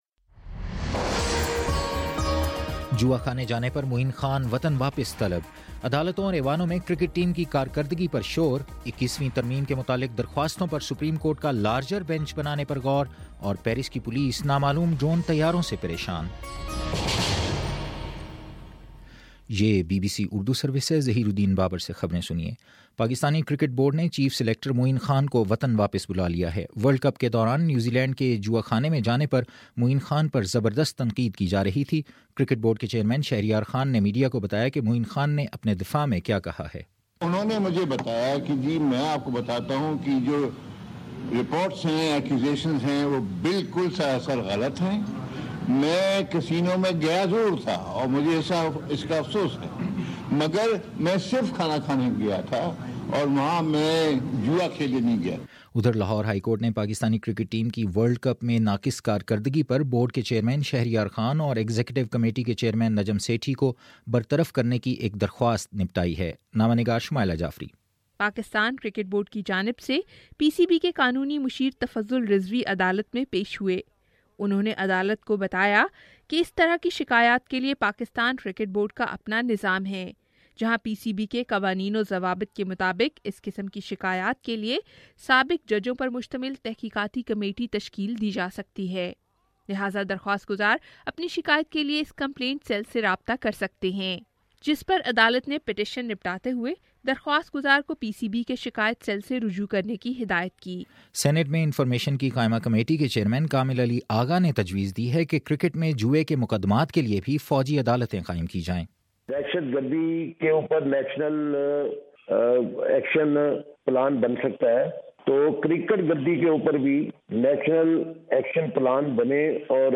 فروری24: شام سات بجے کا نیوز بُلیٹن